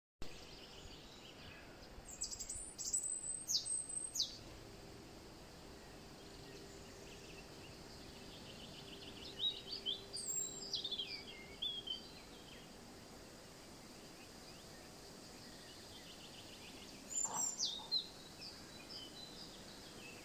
Птицы -> Дроздовые ->
зарянка, Erithacus rubecula
СтатусПоёт